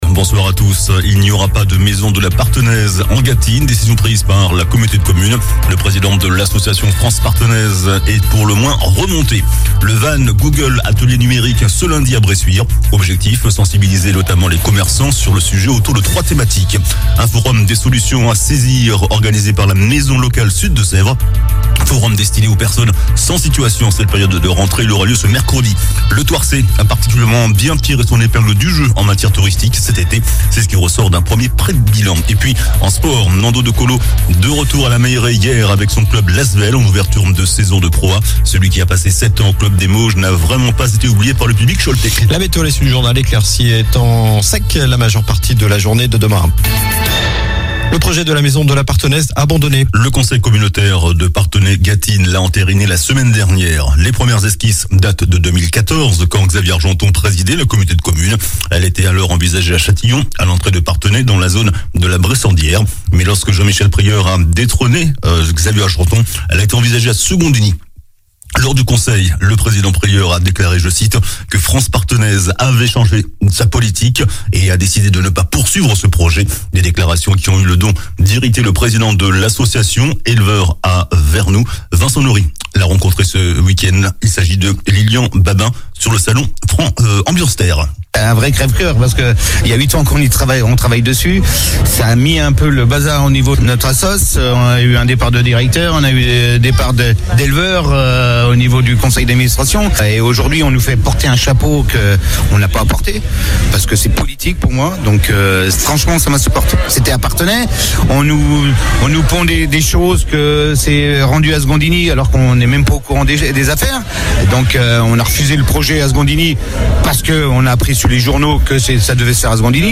Journal du lundi 26 septembre (soir)